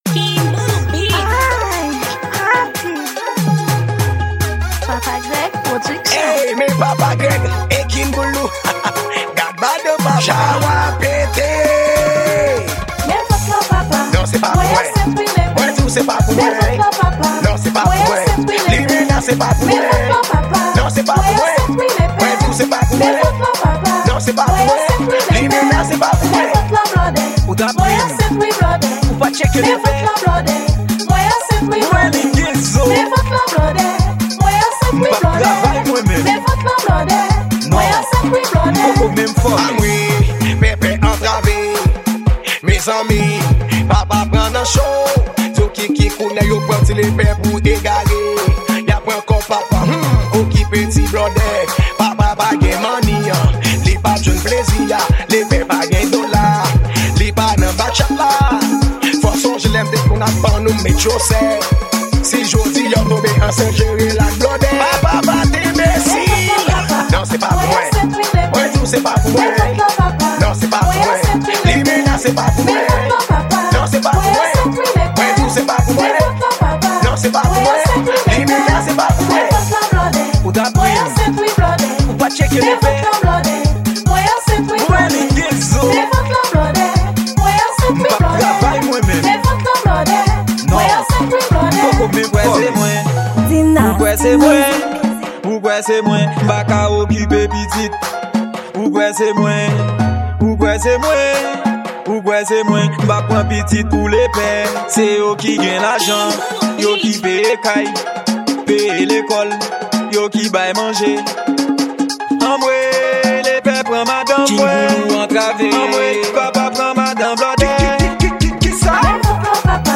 Genre: Raboday.